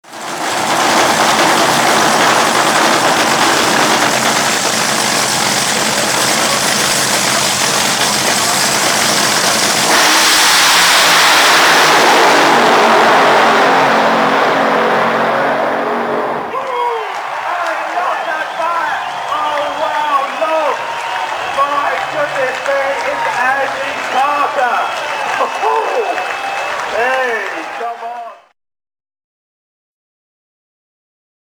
Side by Side Fours at 300 in Europe!